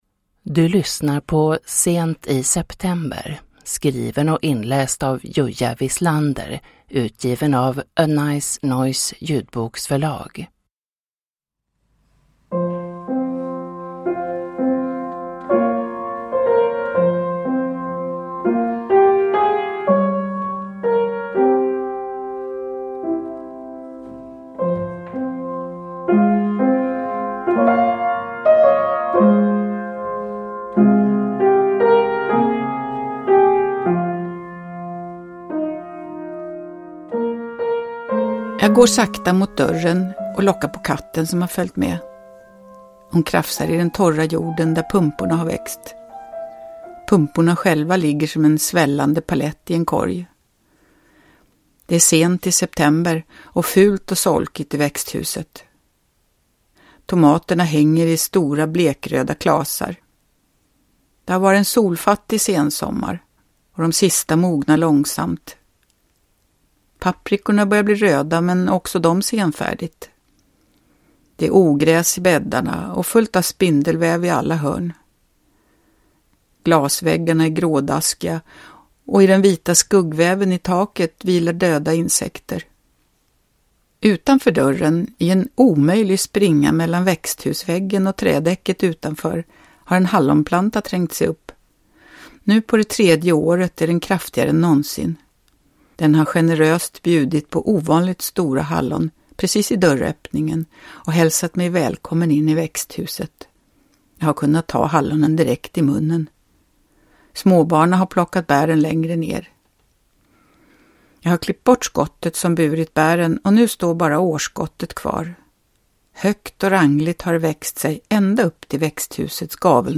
Sent i september – Ljudbok